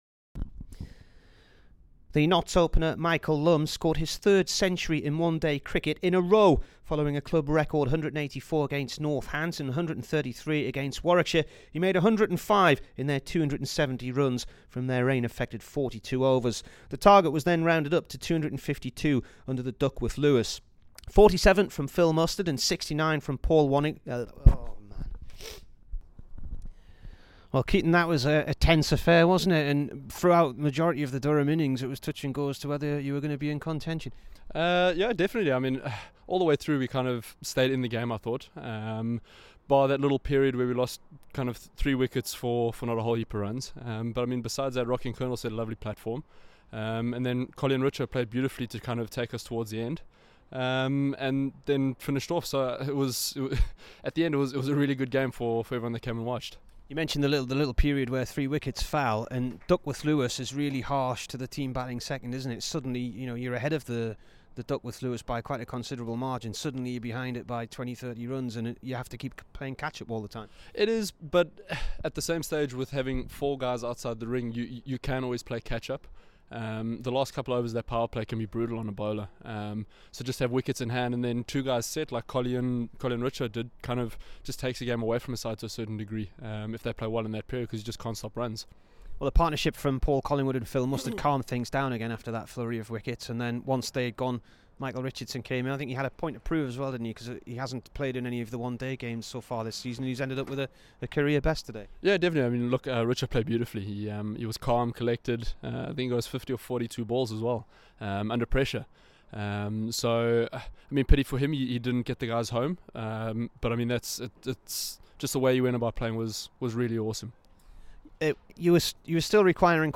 KEATON JENNINGS INT